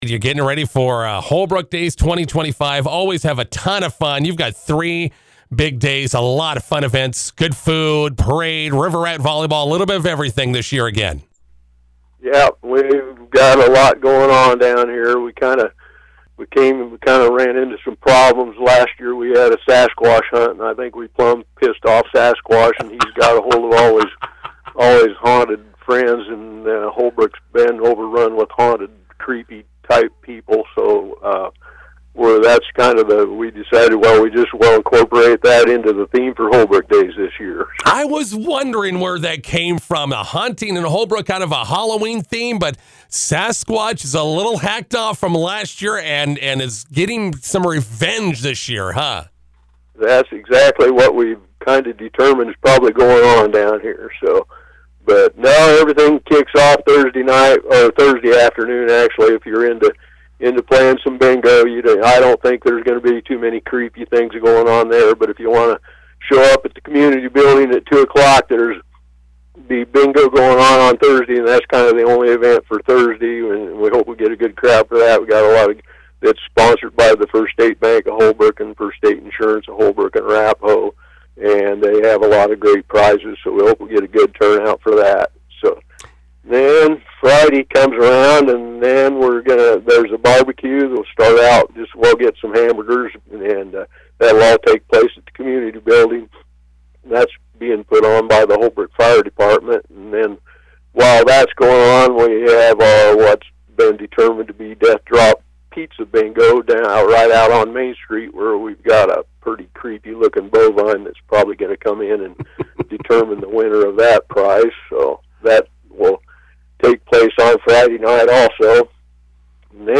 INTERVIEW: Holbrook Days kicking off on Thursday.